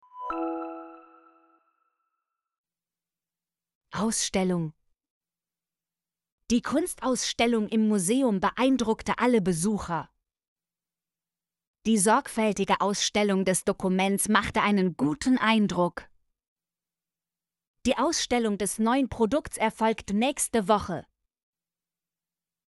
ausstellung - Example Sentences & Pronunciation, German Frequency List